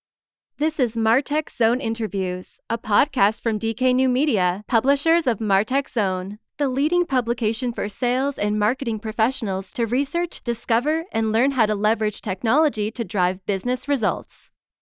Within an hour I had a perfectly executed voice-over that I’m now using in my next episode.
Here’s the podcast intro: